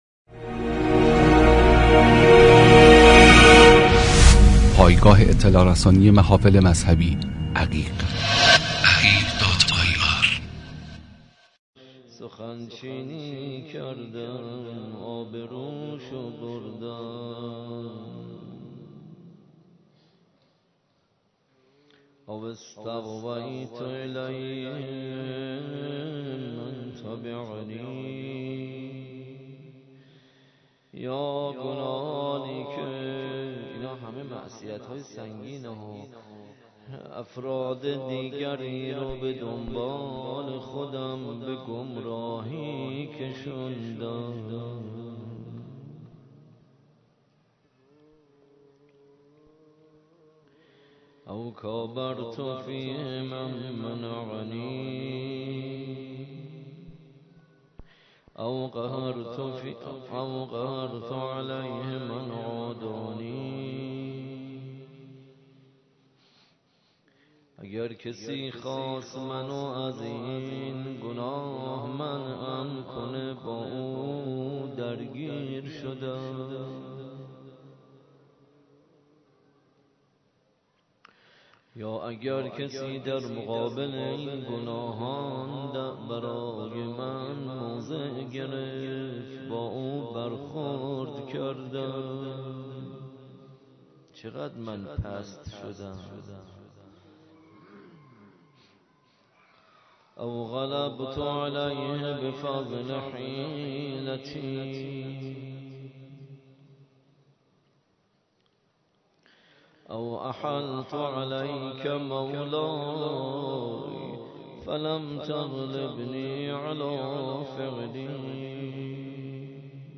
مناجات
ضمنا این مراسم تا پایان ماه مبارک رمضان در این مسجد به نشانی میدان امام حسین(ع) برقرار است.